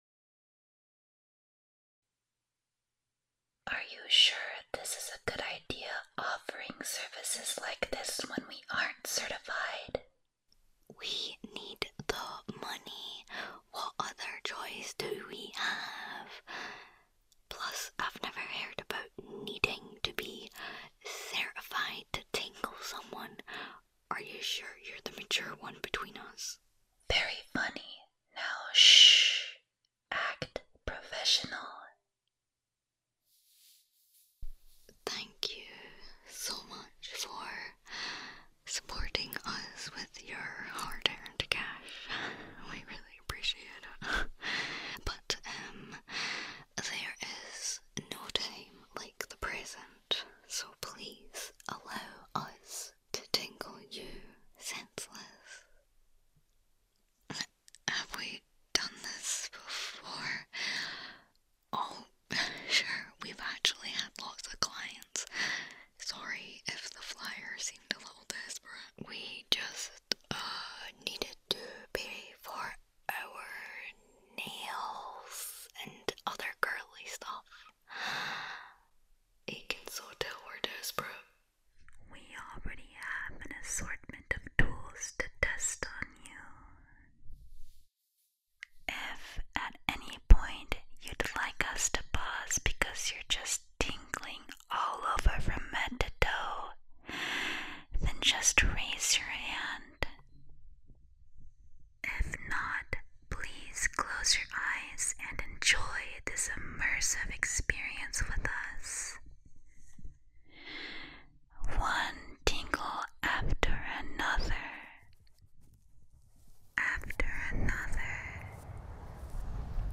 3 When two asmrtists are desperate for money, they set up a quick cash grab pop up asmr studio by coming to your house to tingle you. Although alarm bells are going off you manage to relax and before you know it you are being pampered with layered asmr and can't help resist the call of sleep!
2:20 - Ice Globes, Ear Breashing + Ear Muff 5:10 - Squishy Orb On Ear + Ear Towel On Ear 10:50 - Ear Tapping, Ear Cupping + Ear Squishing 14:30 - Shaving Foam On Mic + Japanese Ear Cleaning Tool 20:00 - Latex Ear Wiping ASMR + Crinkly ASMR 22:30 - Tapping on Plastic, Phone Tapping, Cardboard Tapping + Tapping on Card